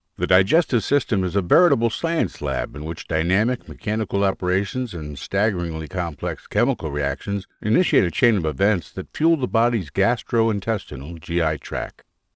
Male
Adult (30-50), Older Sound (50+)
E-Learning
Straight Forward Clear Read
0525eLearning_Demo.mp3